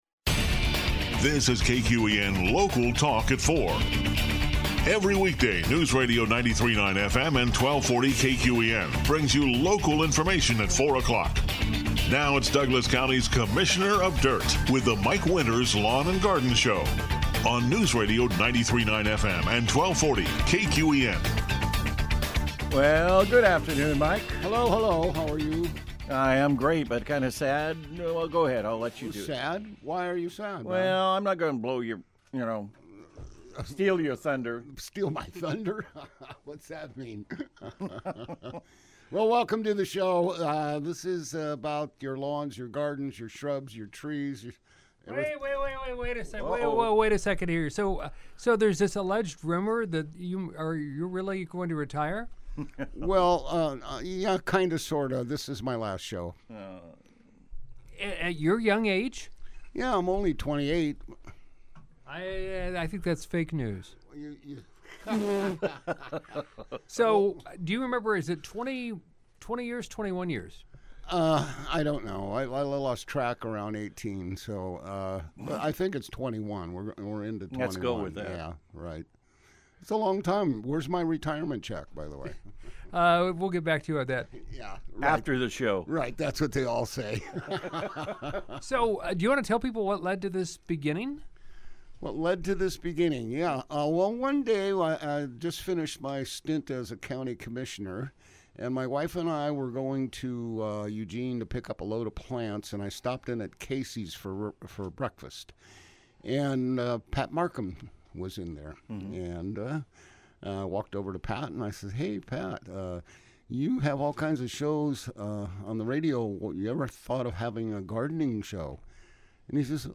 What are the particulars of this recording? has his final live local lawn and garden program.